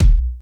keys_32.wav